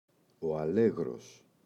αλέγρος [aꞋleγros]